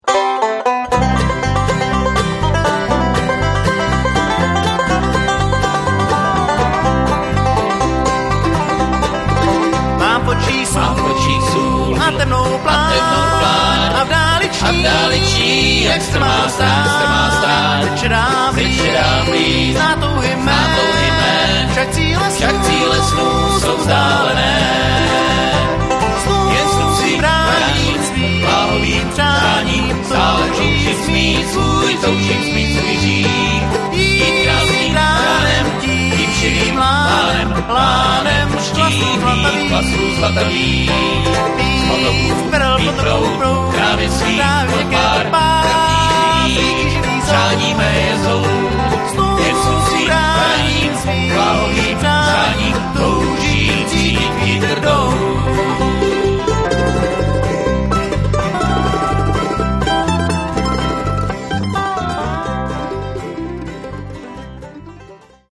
guitar, vocal
mandolin, vocal